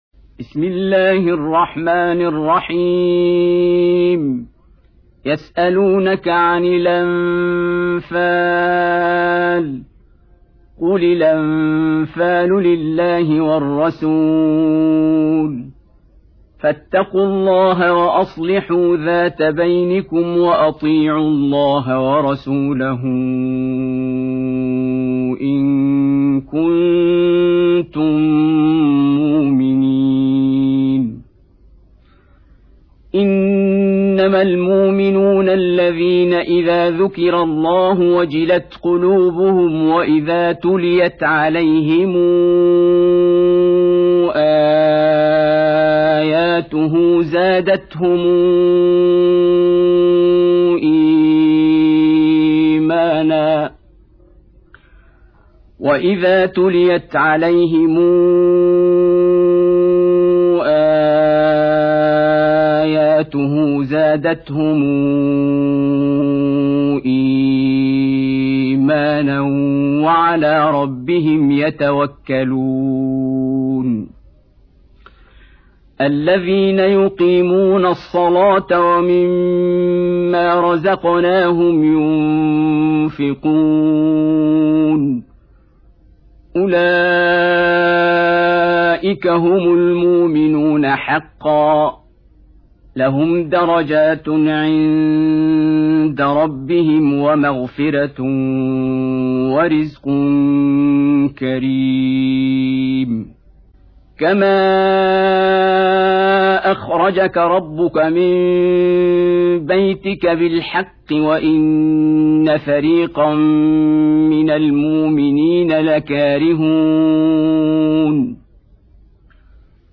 8. Surah Al-Anf�l سورة الأنفال Audio Quran Tarteel Recitation
Surah Repeating تكرار السورة Download Surah حمّل السورة Reciting Murattalah Audio for 8. Surah Al-Anf�l سورة الأنفال N.B *Surah Includes Al-Basmalah Reciters Sequents تتابع التلاوات Reciters Repeats تكرار التلاوات